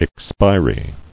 [ex·pi·ry]